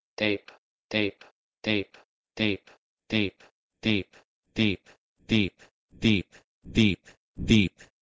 E.g.3. Similarly, Middle English "deep" had a mid, front vowel, but in modern English "deep" has a close, front vowel, so the change was something like this (listen):
dEEp-to-diip.wav